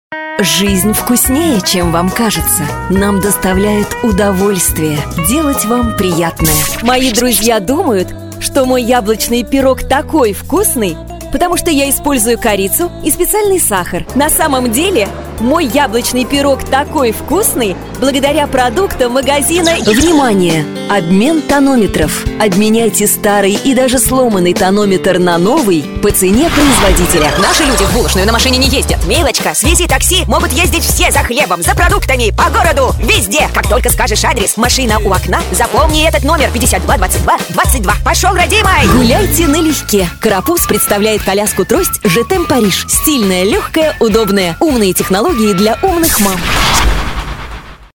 Великолепное меццо-сопрано.
Презентабельный красивый голос профессиональной актрисы.